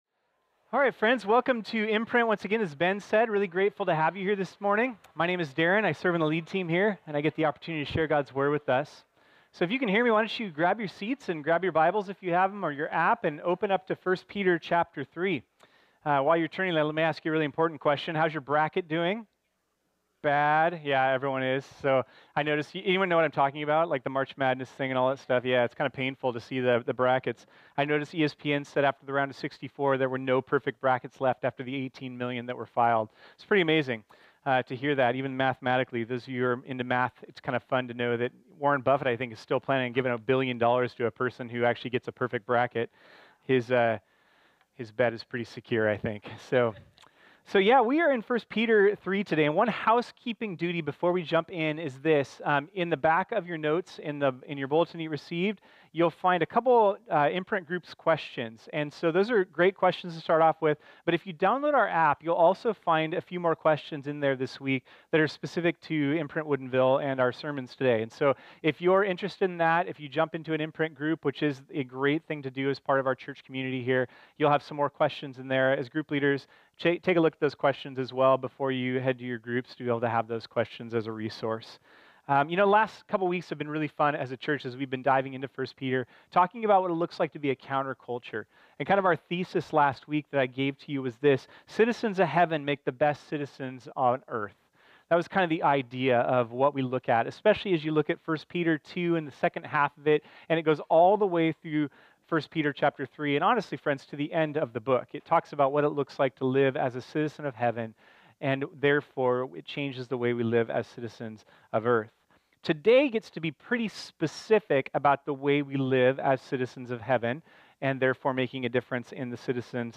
This sermon was originally preached on Sunday, March 18, 2018.